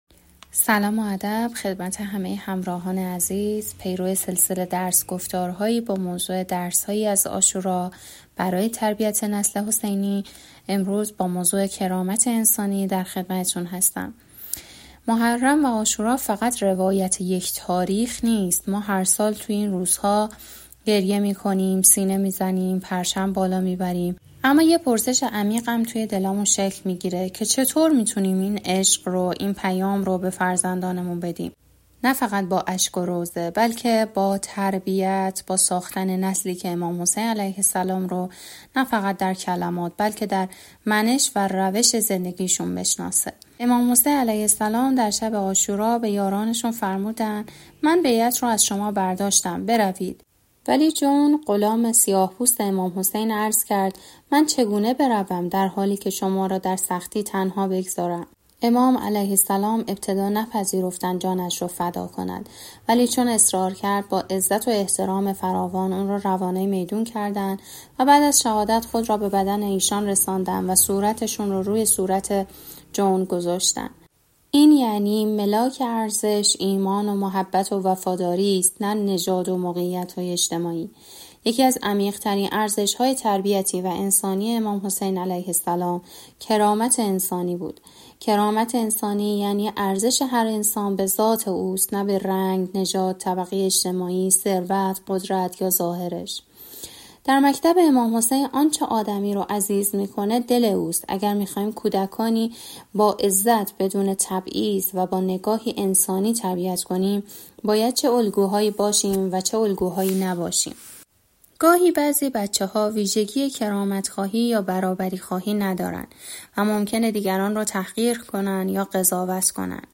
درس‌گفتار‌هایی